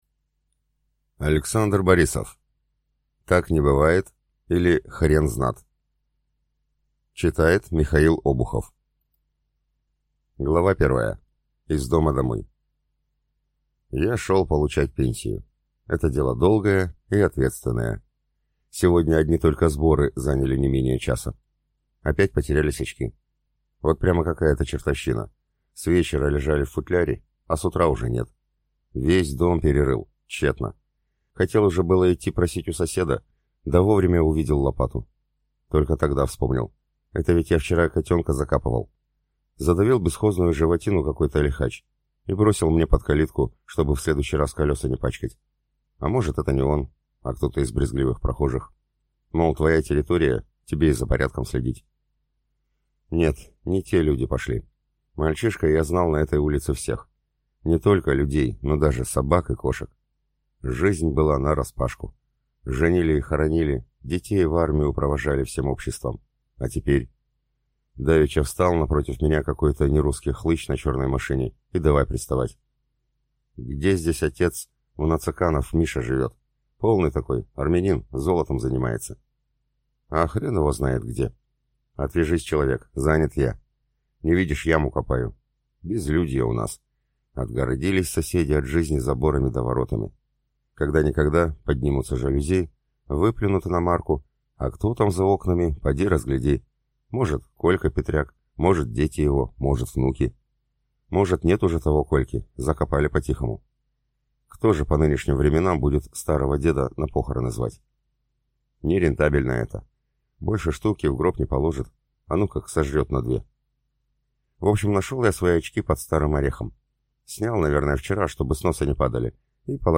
Аудиокнига Так не бывает, или Хрен знат | Библиотека аудиокниг